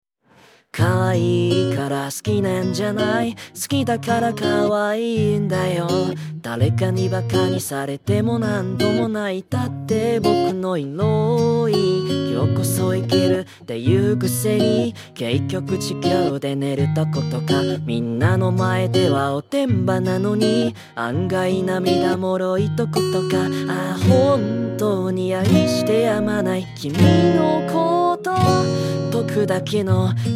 唱歌表现